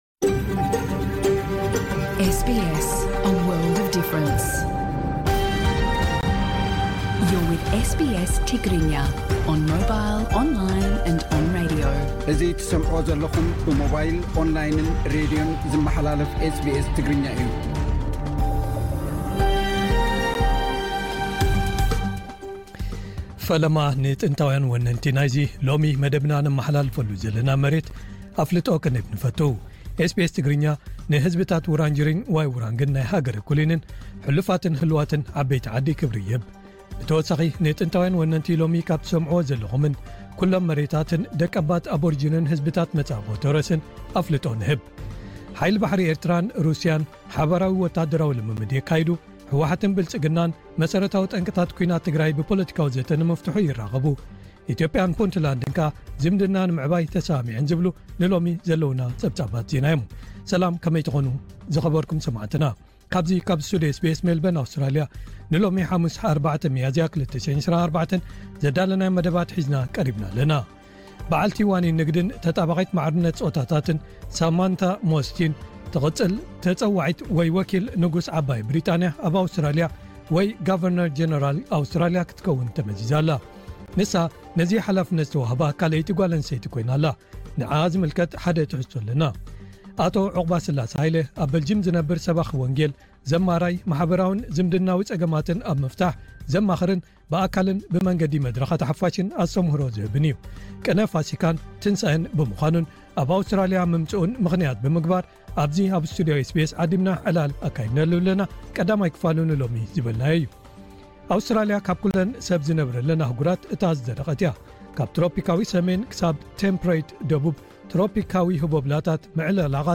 ዜና ኤስ ቢ ኤስ ትግርኛ (4 ማዝያ 2024)